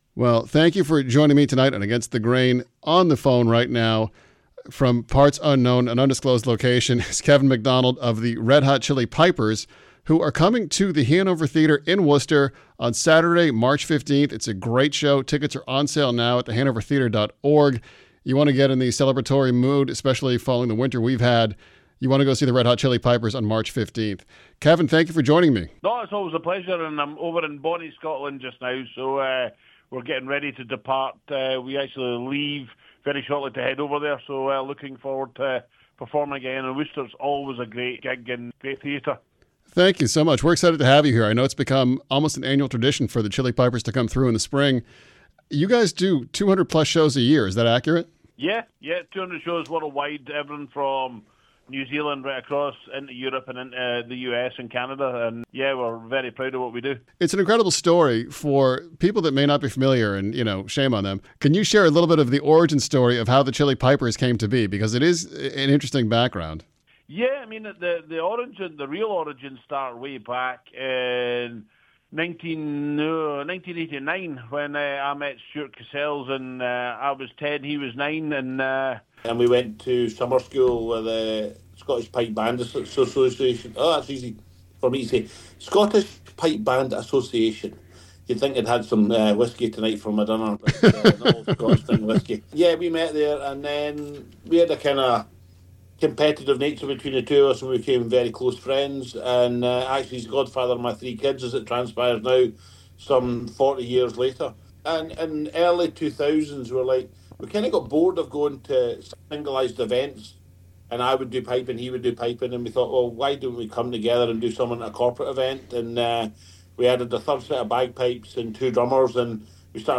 This interview features a fun, wide-ranging conversation about music, culture, and what makes the Chilli Pipers such a unique experience for fans: